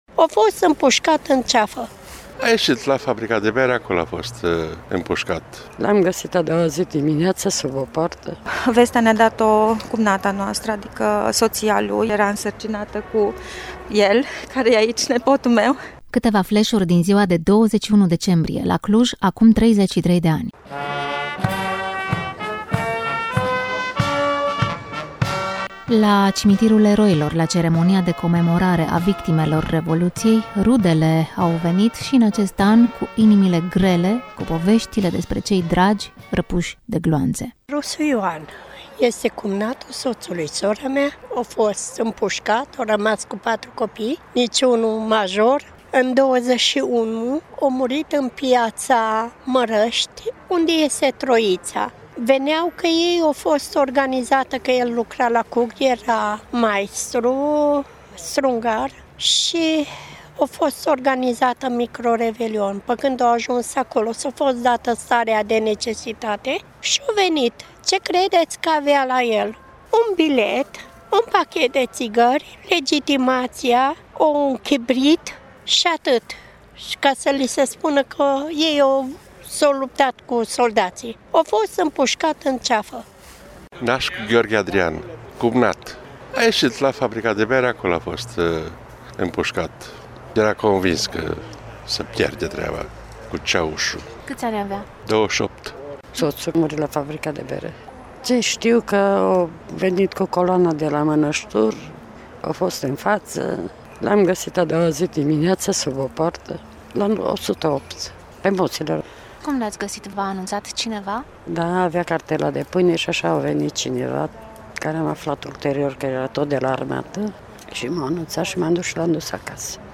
21 decembrie 1989 este ziua în care se ieșea în stradă și la Cluj. Au trecut de atunci, iată, 33 de ani, iar astăzi la mormintele martirilor din Cimitirul Eroilor din Cluj-Napoca s-au adunat rudele acestora și participanți la evenimentele din ’89 pentru a-i omagia pe revoluționarii martiri. Am fost și noi acolo: